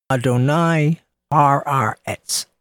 ah-doh-nye ha-ahr-etz
ah-doh-nye-ha-ahr-etz.mp3